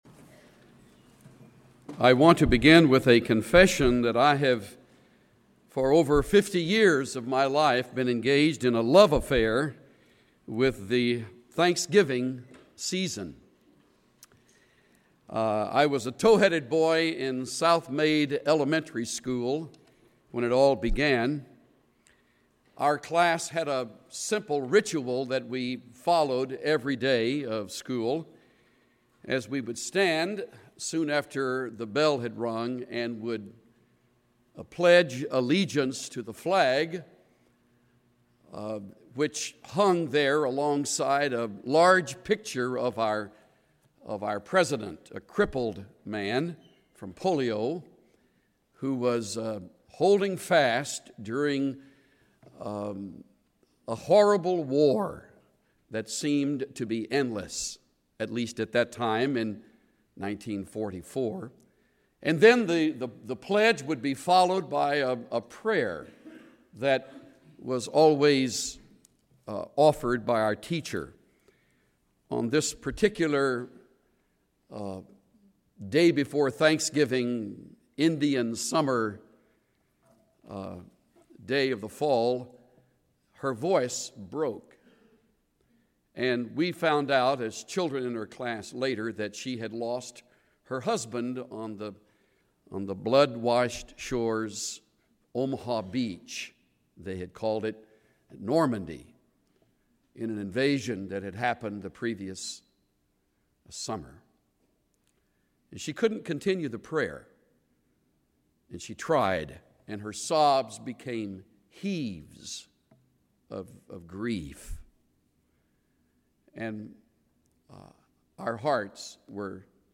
Personal singing and reflection
Final prayer of thanksgiving and intercession